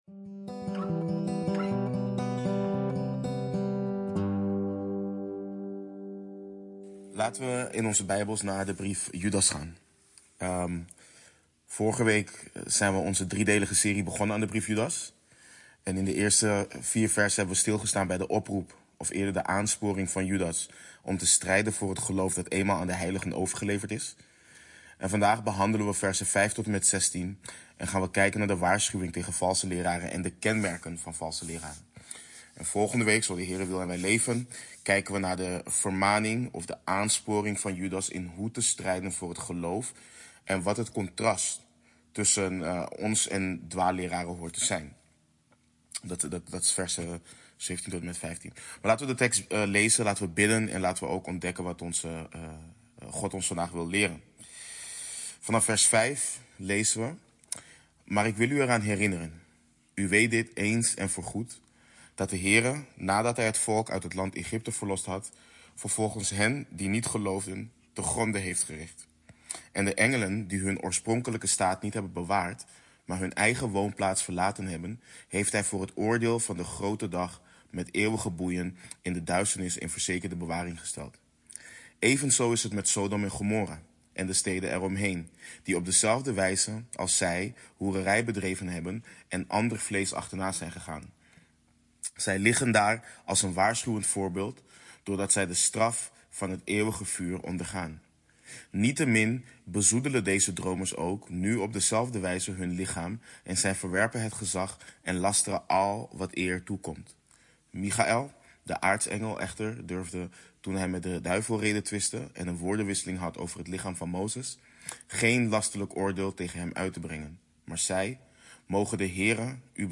Beluister de preek en uitleg van Judas 1:5-16 | Waarschuwing tegen- en kenmerken van valse leraren. Het Woord van God voorgelezen, verklaard en toegepast.
Beluister Bijbelstudie